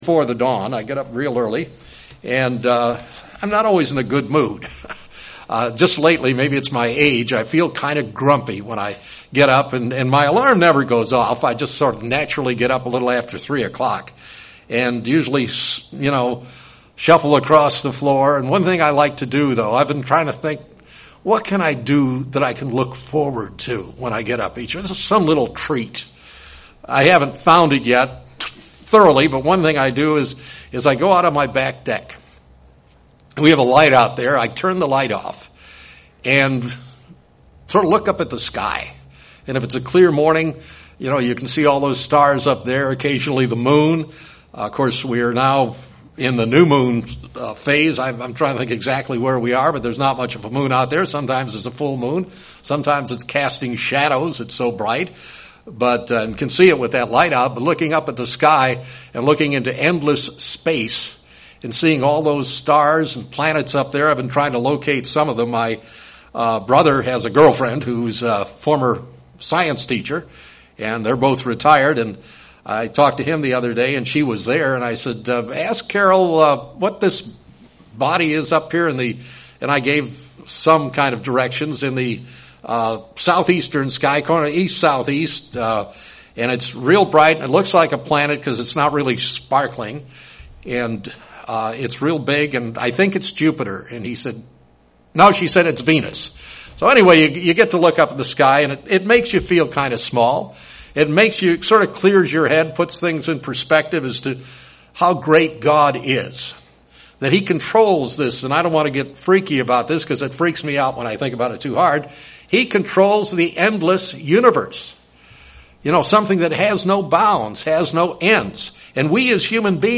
This sermon looks into God's greatest power, love.
Given in Springfield, MO